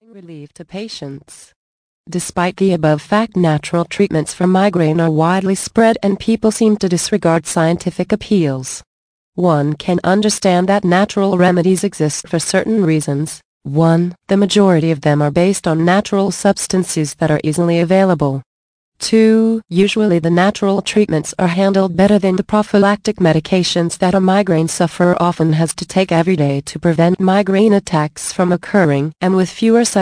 Thanks for your interest in this audio book.